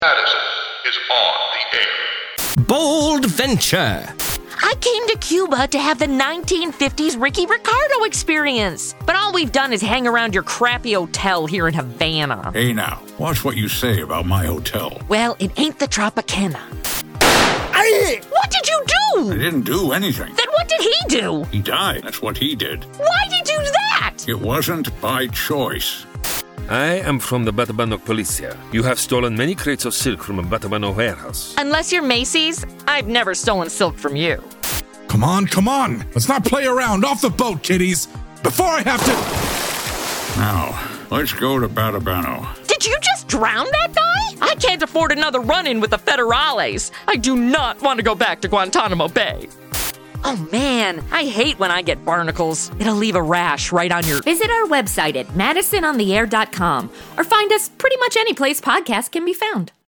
Format: Audio Drama
Voices: Full cast
Genres: Comedy, Adaptation, Old time radio